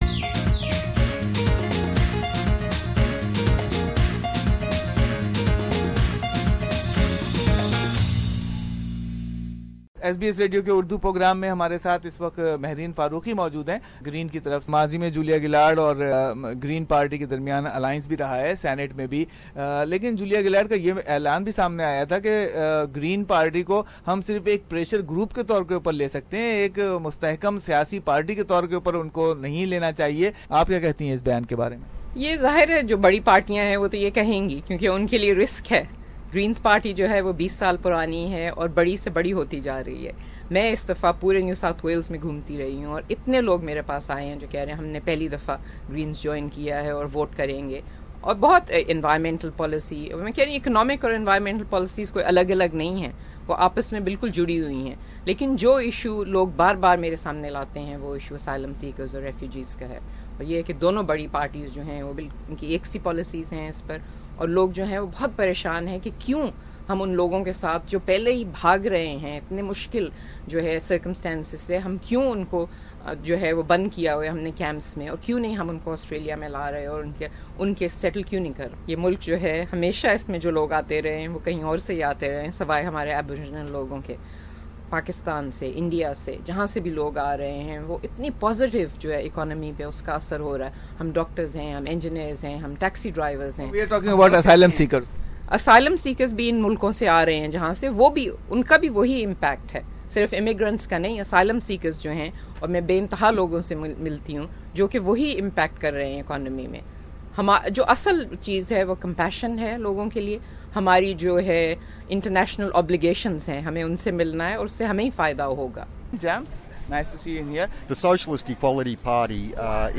The SBS Election Exchange is a series of community-based events hosted by SBS Radios Language programs in the lead up to the 2016 Federal Election. SBS Radio invited locals to come along, meet their local candidates and join the debate. Listen what we heard at Bansktown Sydney Event on 25th.